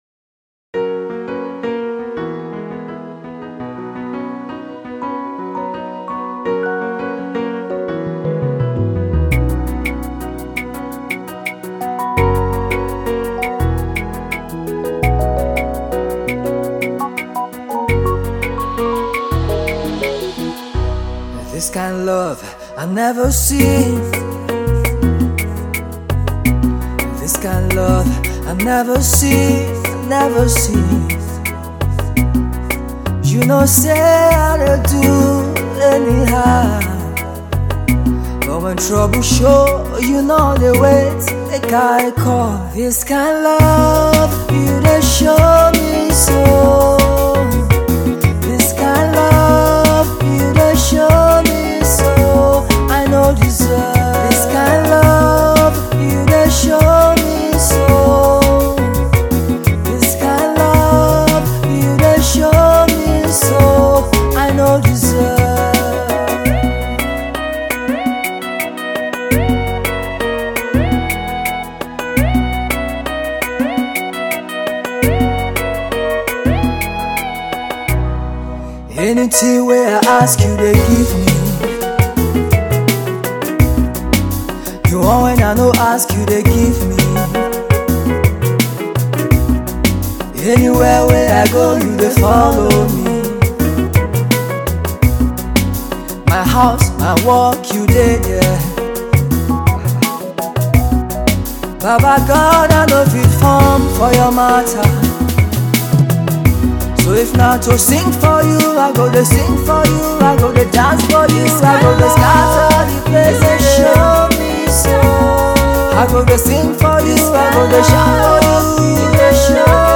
The song is highlife and it is sung in pigin English.
He is a contemporary gospel singer and song writer.